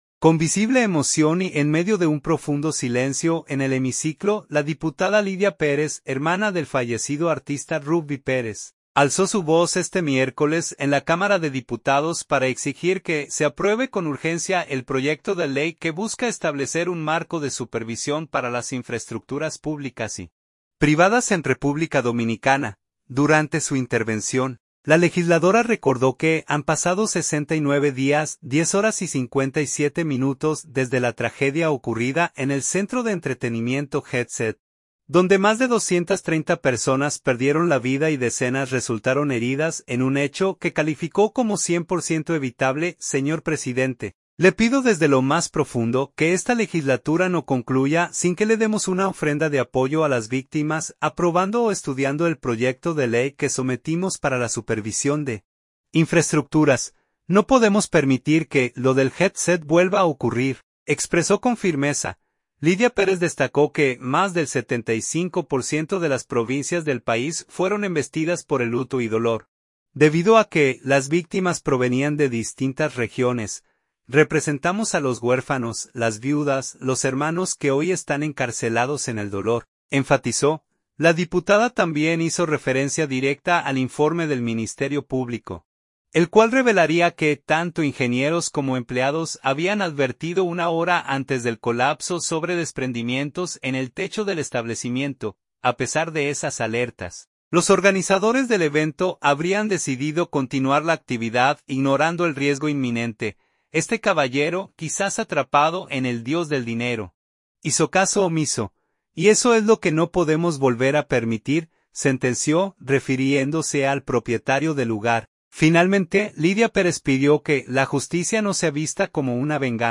SANTO DOMINGO. – Con visible emoción y en medio de un profundo silencio en el hemiciclo, la diputada Lidia Pérez, hermana del fallecido artista Rubby Pérez, alzó su voz este miércoles en la Cámara de Diputados para exigir que se apruebe con urgencia el proyecto de ley que busca establecer un marco de supervisión para las infraestructuras públicas y privadas en República Dominicana.
“Señor presidente, le pido desde lo más profundo: que esta legislatura no concluya sin que le demos una ofrenda de apoyo a las víctimas aprobando o estudiando el proyecto de ley que sometimos para la supervisión de infraestructuras. No podemos permitir que lo del Jet Set vuelva a ocurrir”, expresó con firmeza.